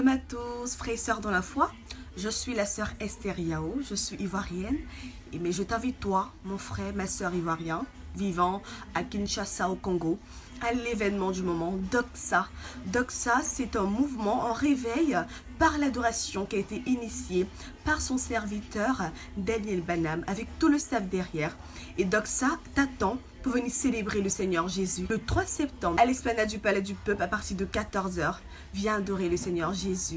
Voix off
Publicité pour l’événement
Voix - Contralto